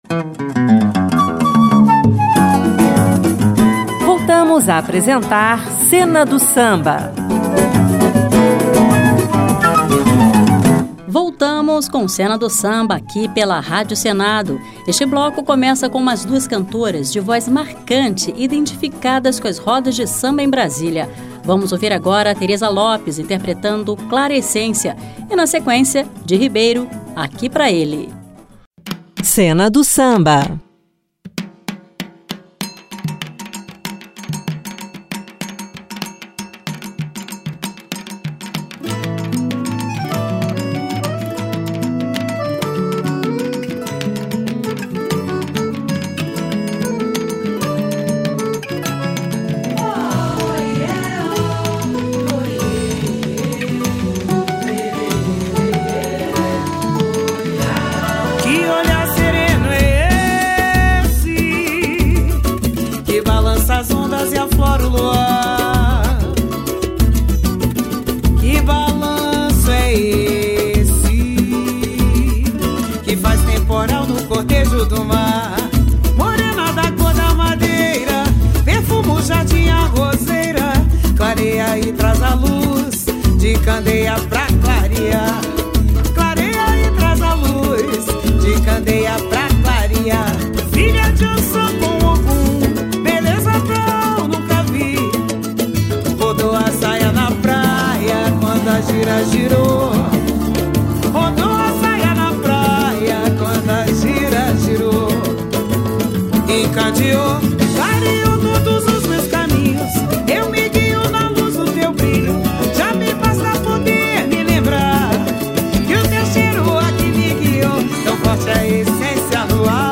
sambas-enredo